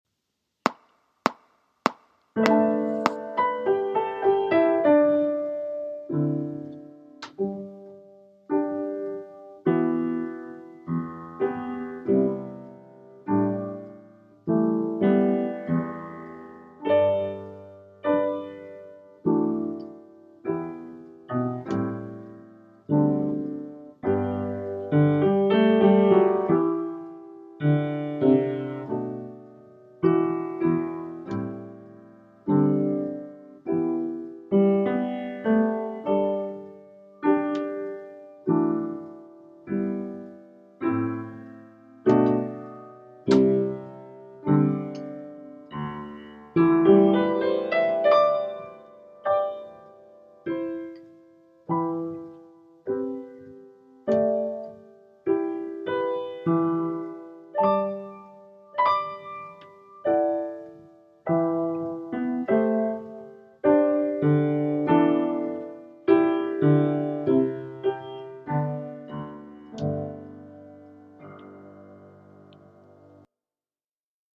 Liuylang River Piano Only